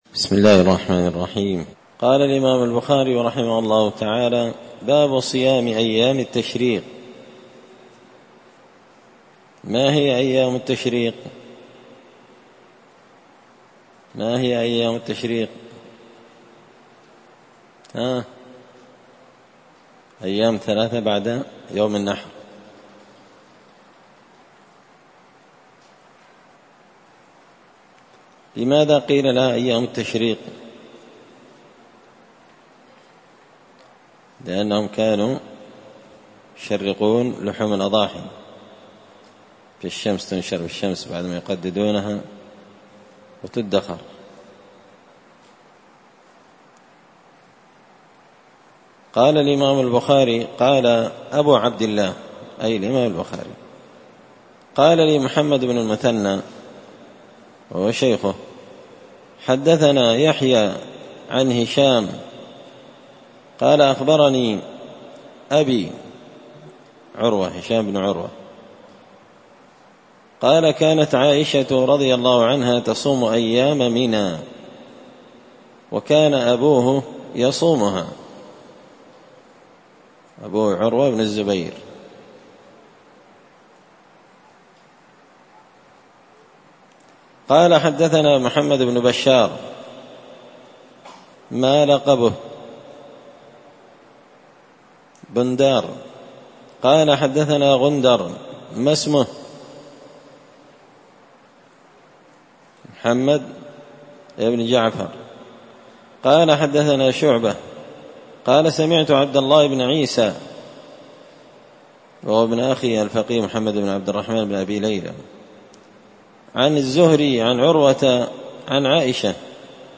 الدرس الثالث والخمسون (53) باب صيام أيام التشريق